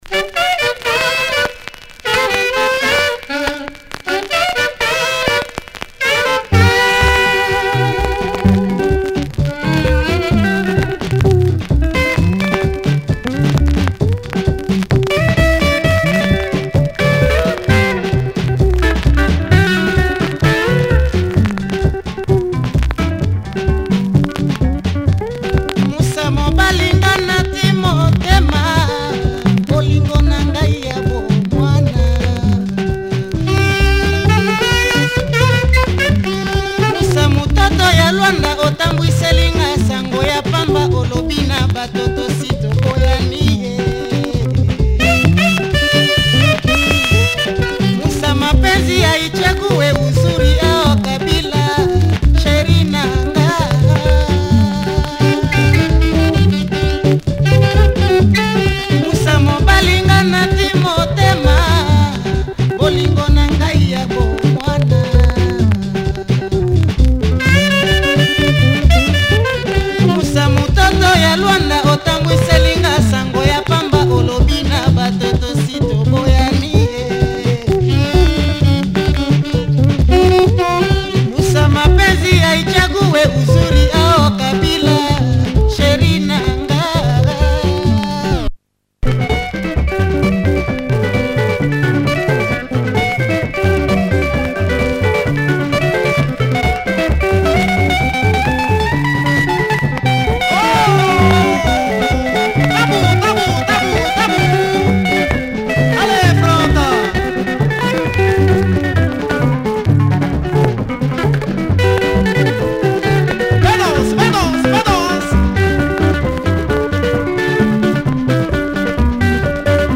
Super Lingala single